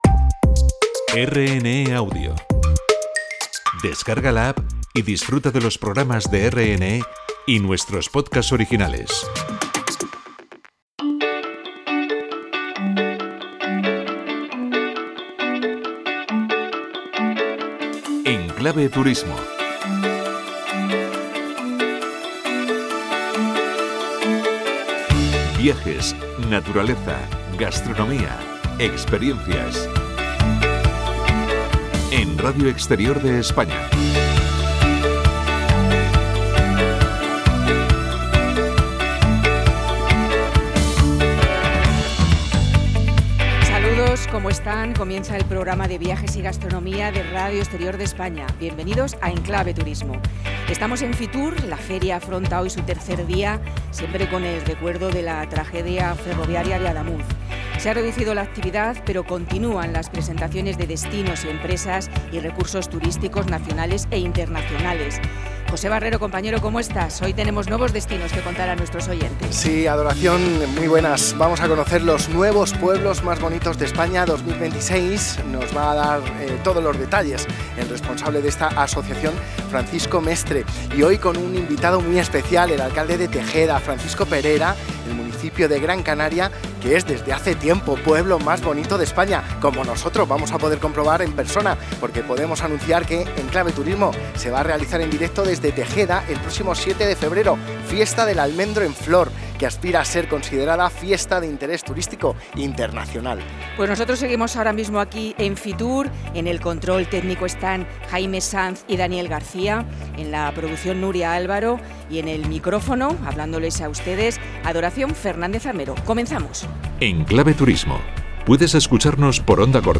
Entrevista a Francisco Perera, alcalde de Tejeda - Fitur 2026
Entrevista del programa En clave Turismo REE en Fitur a Francisco Perera, alcalde de Tejeda, a partir del minuto 16:45 aproximadamente, sobre la celebración de las Fiesta del Almendro en Flor, de Interés Turístico Nacional y que aspira a la categoría de Fiesta Internacional.
EntrevistaEnclaveTurismoTejedaFitur2026WEB.mp3